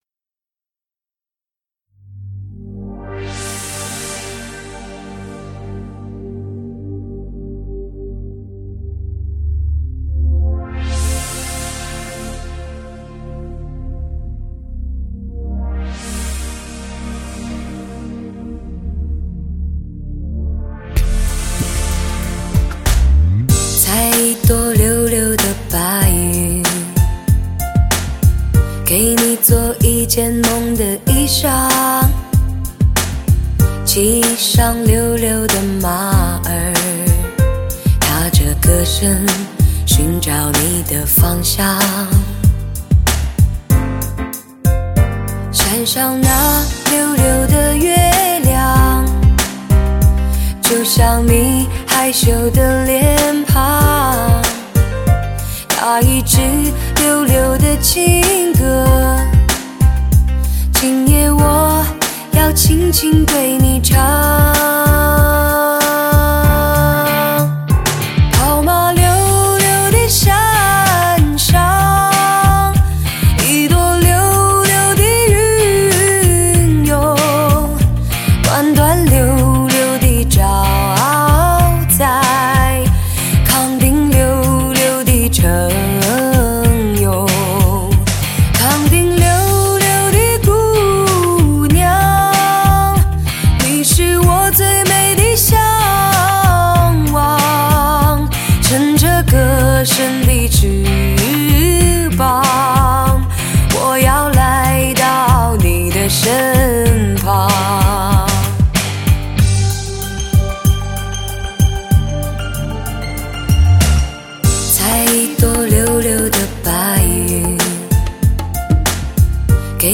极品测试Ⅳ HI-FI顶级人声测试天碟
最具Hi-Fi震撼的发烧女声，一旦拥有，别无所求；此声一起，发烧无极